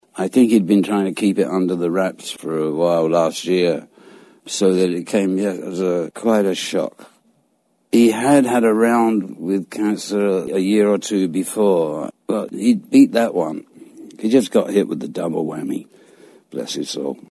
Keith Richards confirms that it was cancer that killed Charlie Watts last August at the age of 80. [Courtesy of CBS Sunday Morning]